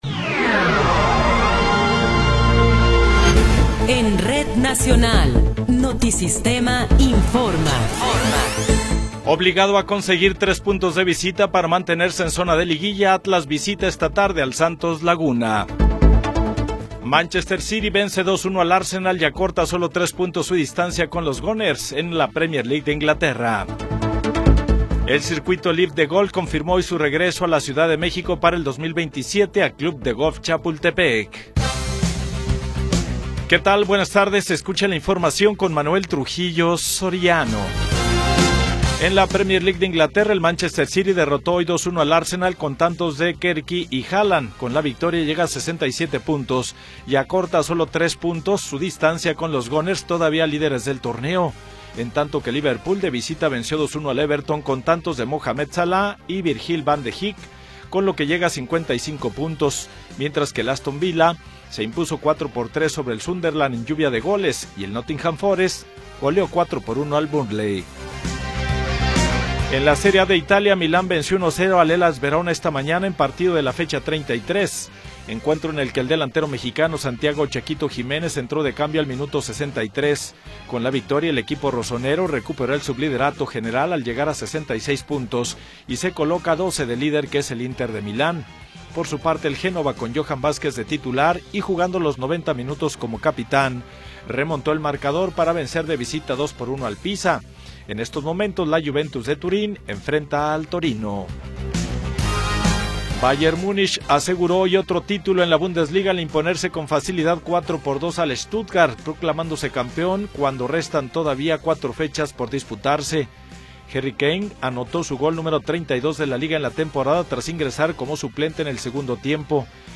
Noticiero 14 hrs. – 19 de Abril de 2026
Resumen informativo Notisistema, la mejor y más completa información cada hora en la hora.